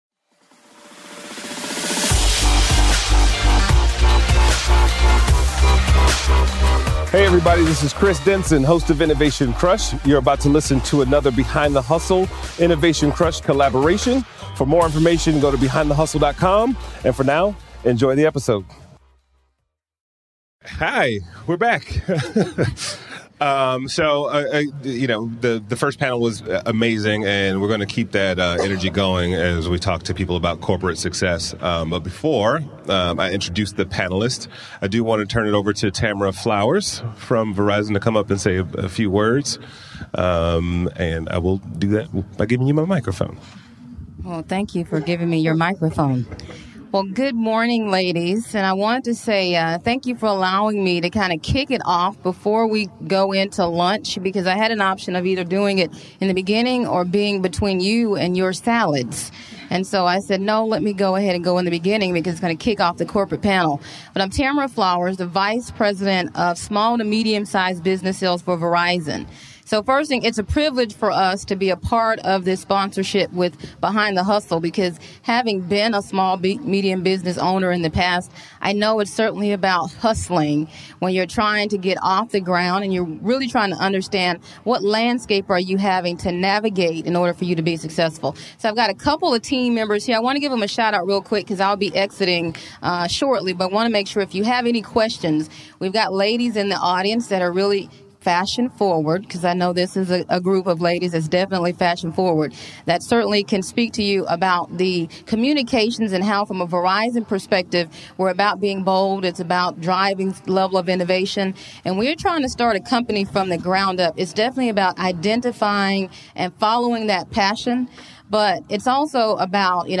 On this episode of Innovation Crush, we got a chance to sit down with the power women of Nike, Def Jam, and Universal Pictures during Behind the Hustle's "Yes Ma'am" event. The conversation is a fun and dynamic ride through everything from humble beginnings, personal branding, the value of relationships, working with millenials, celebrating personal victories, work-life balance and more... plus Q & A from the live audience!